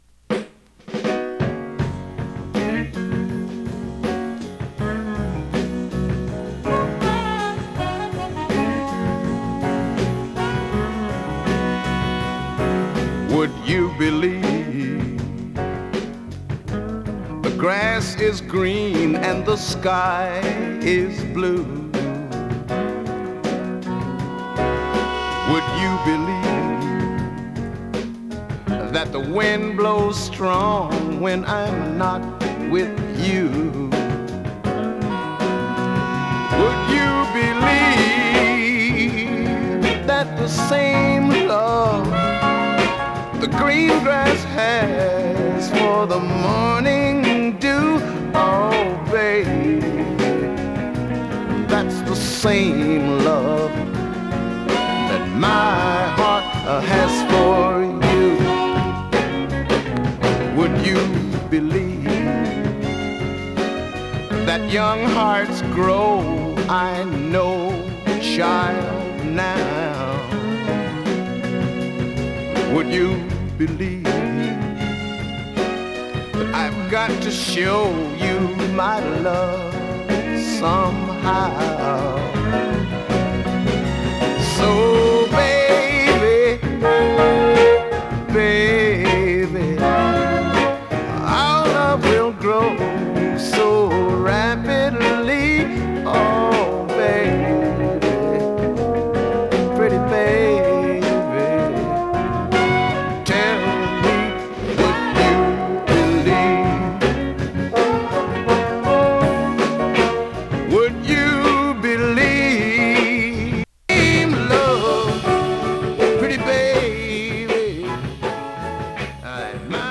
＊音の薄い部分で時おり軽いチリ/パチ・ノイズ。